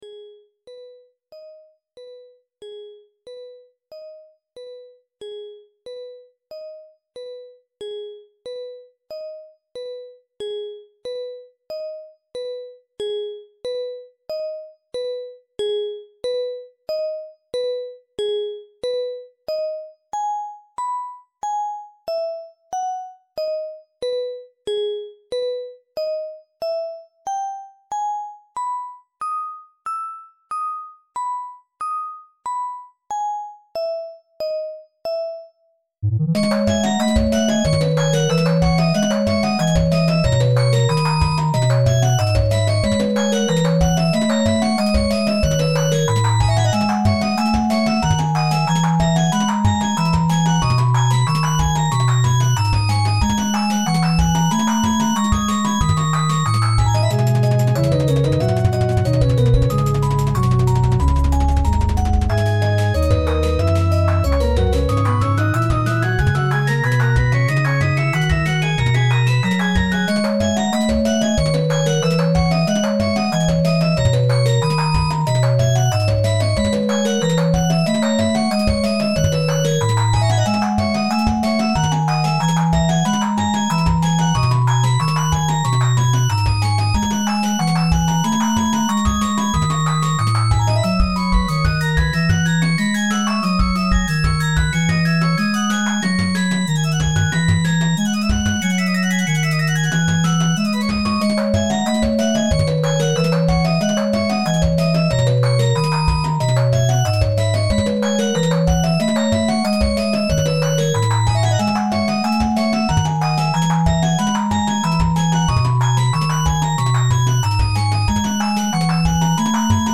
Fight theme for the Ender Dragon since the existing one is basically just ambient noise; I really like this one.